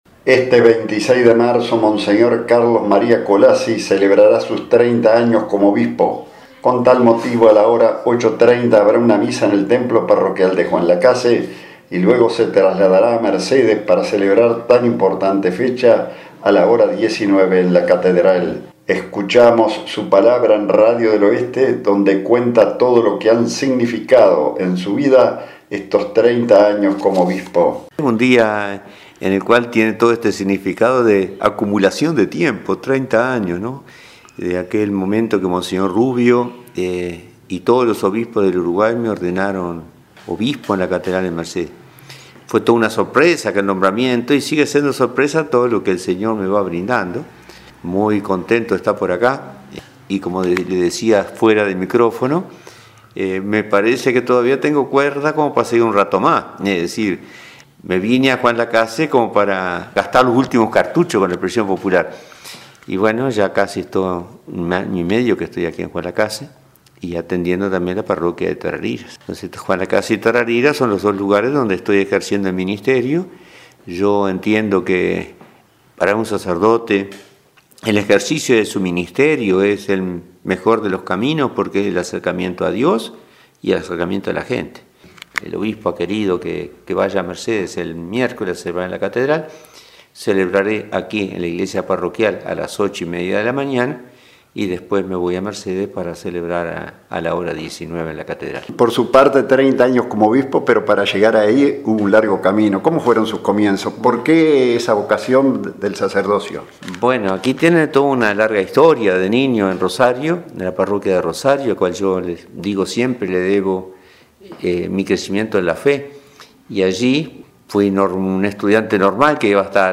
Escuchamos su palabra en Radio del Oeste donde nos cuenta todo lo que han significado en su vida estos 30 años como obispo.